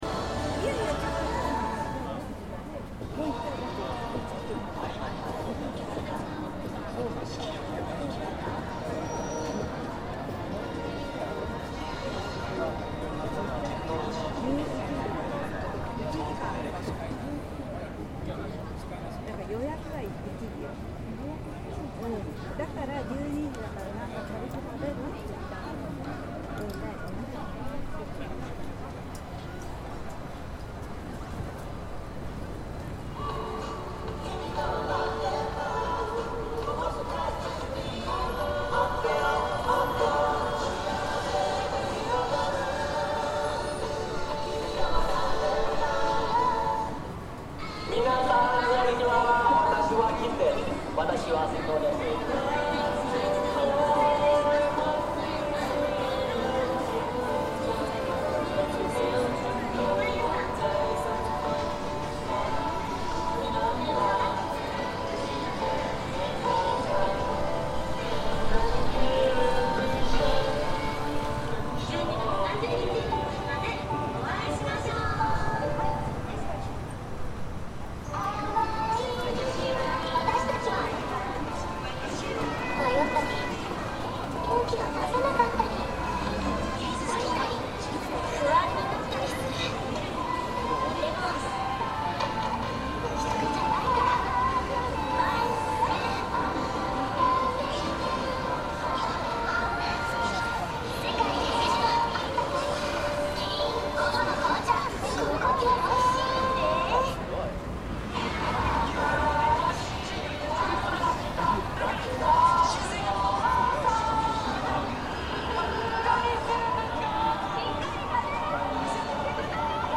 Shibuya Crossing